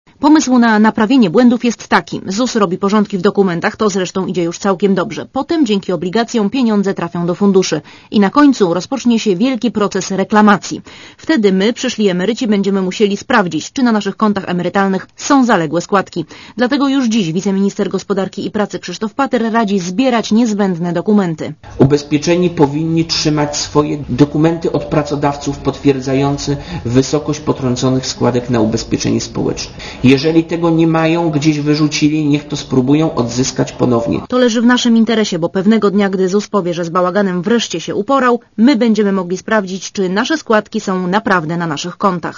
Posłuchaj relacji reporterki Radia Zet (165 KB)